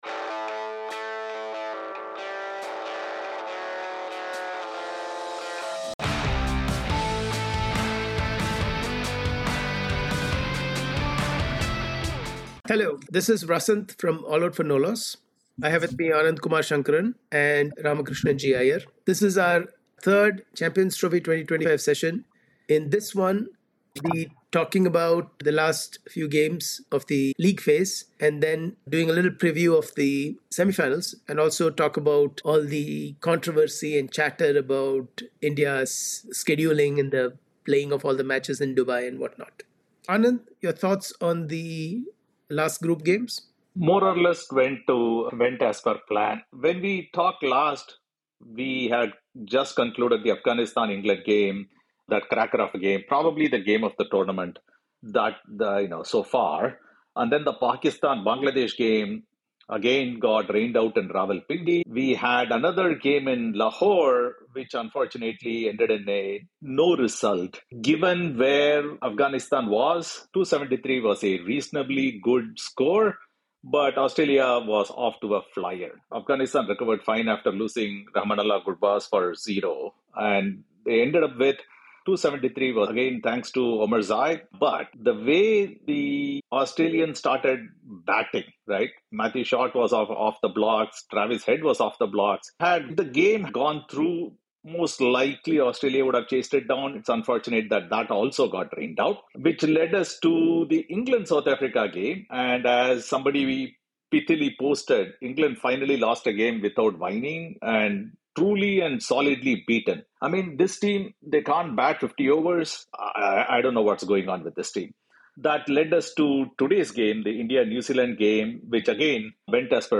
In this conversation we will take a look at the last 4 games of the league phase and will do a preview of the semi-finals During the chat we touch upon the following questions - Is India unstoppable ?